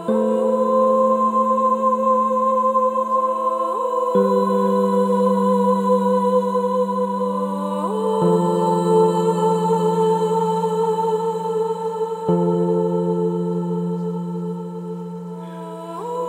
Ребята подскажите где найти вокальные вставочки такие)